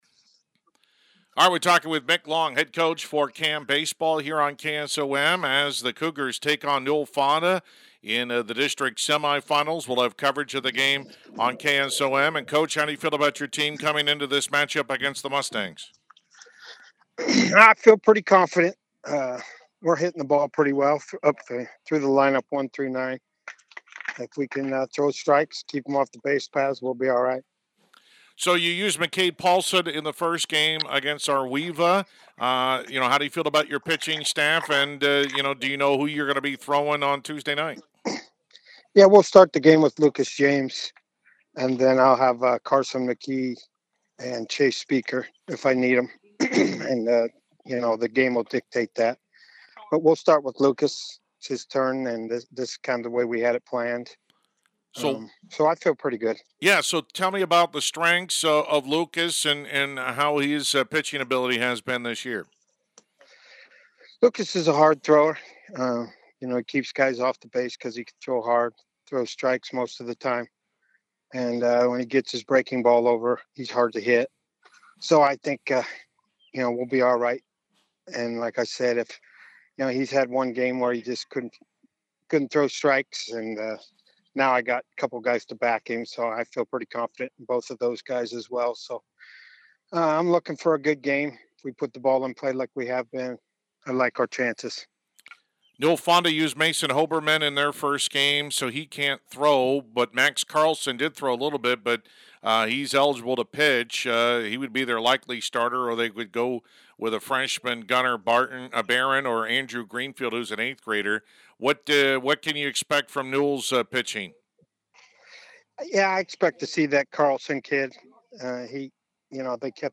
Complete Interview